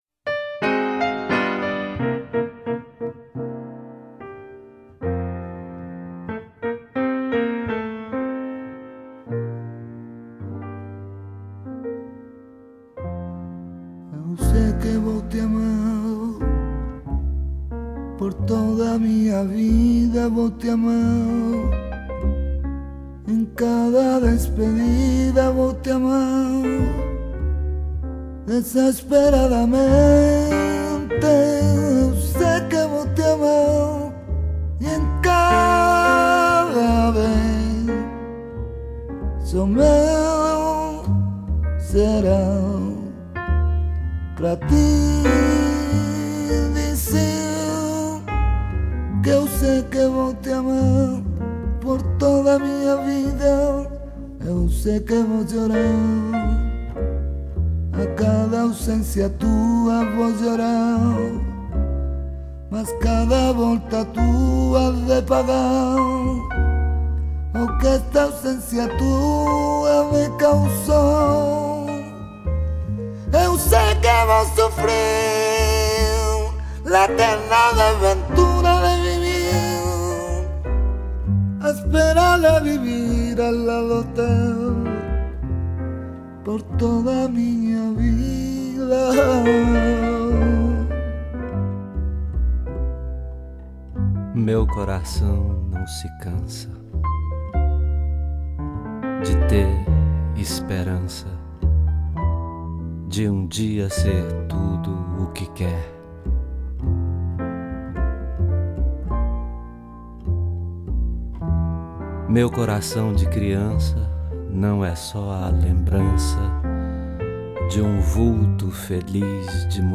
Dont la bossa-nova.